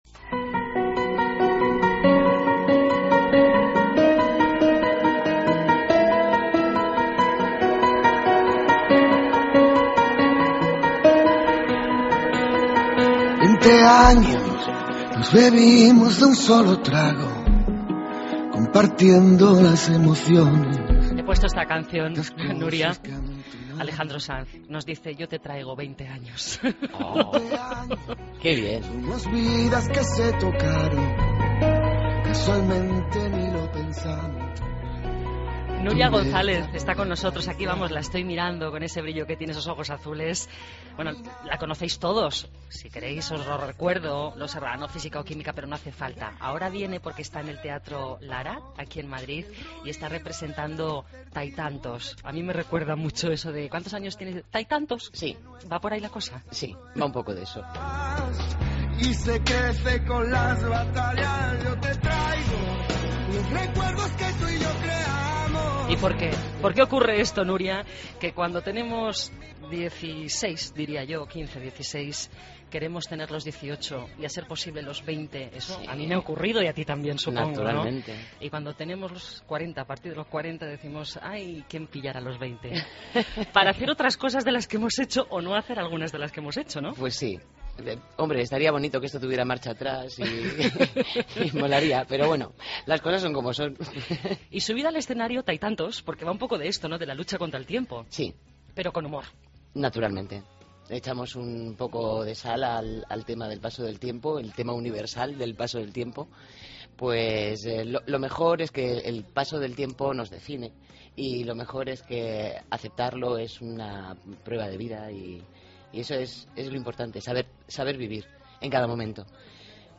Escucha la entrevista a la actriz Nuria González